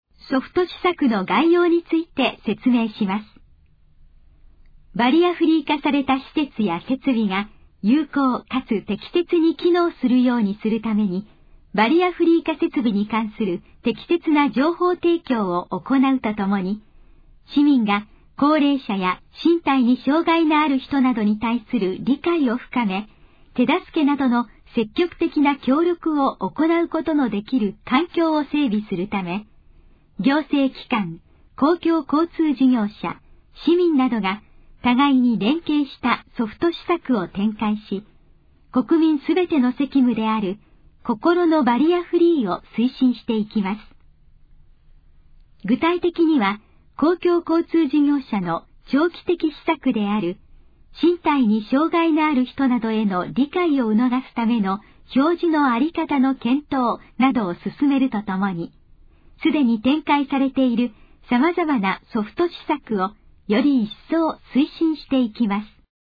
以下の項目の要約を音声で読み上げます。
ナレーション再生 約133KB